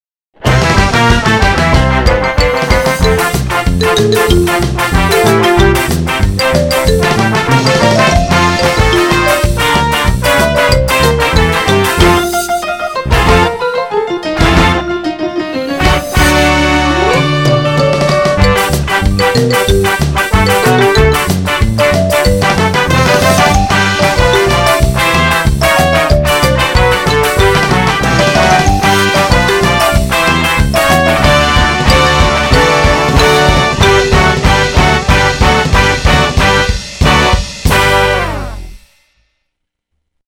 まだカラオケの状態なので、ここに歌を入れてくれるボーカルを探しています。
ポップス, スカ/ロカビリー